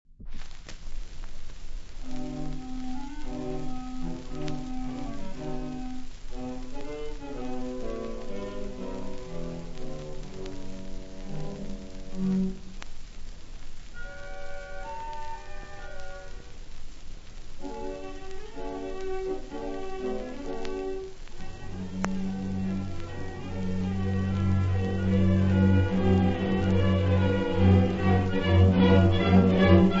• minuetti
• orchestre
• registrazione sonora di musica